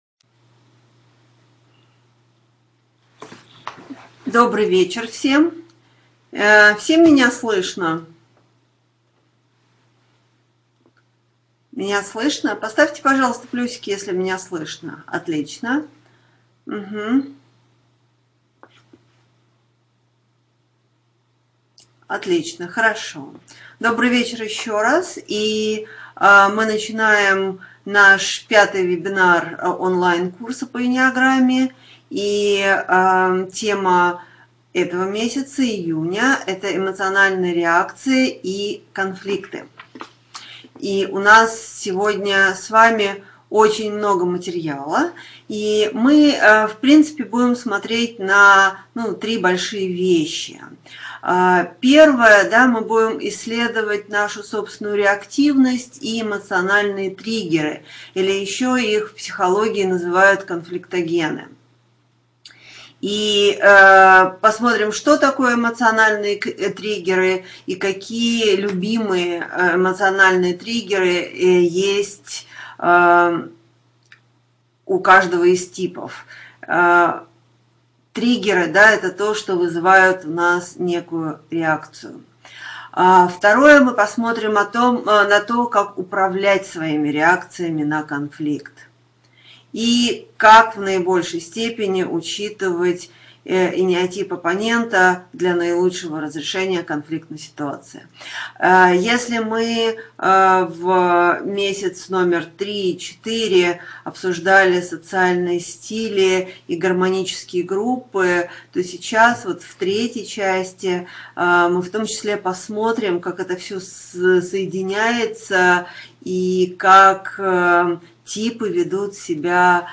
Аудиокнига Эмоциональные реакции и конфликты | Библиотека аудиокниг